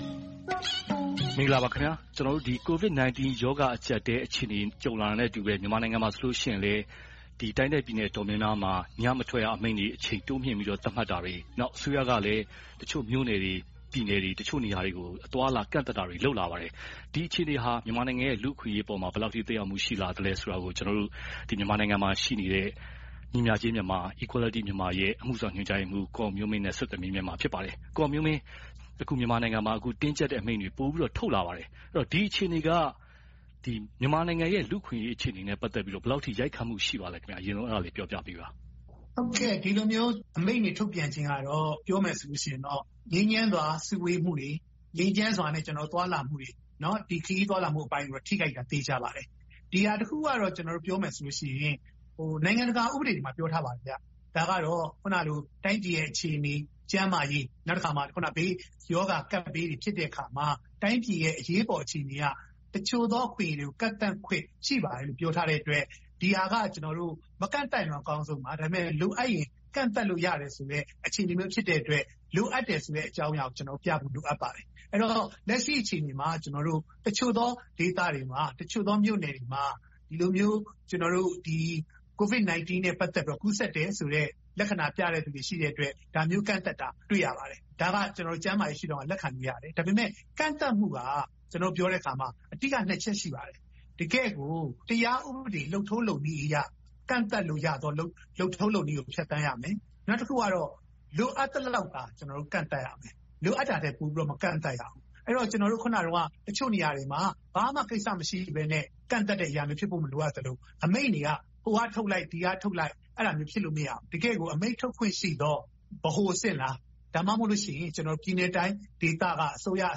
Skype ကတဆင့် ဆက်သွယ်မေးမြန်းထားပါတယ်။